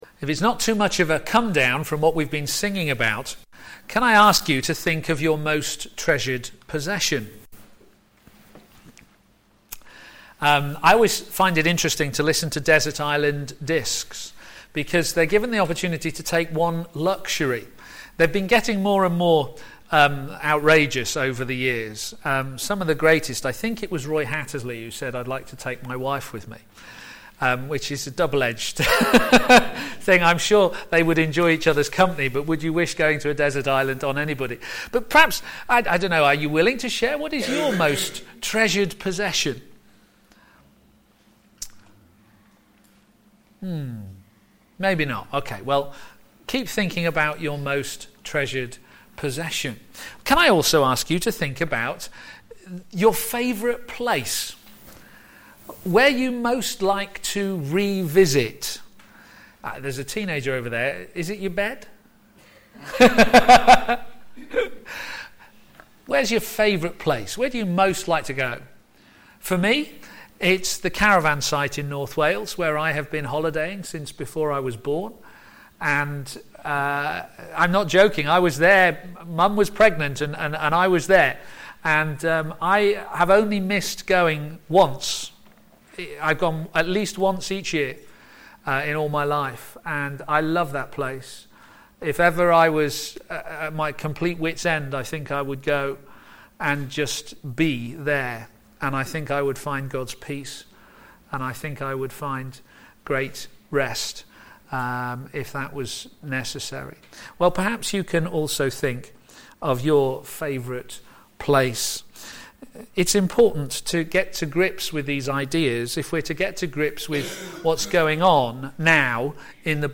Media Library Media for p.m. Service on Sun 23rd Nov 2014 18:30 Speaker
1-6 Series: True Pilgrimage? Theme: -to be in His place and under His law Sermon In the search box below, you can search for recordings of past sermons.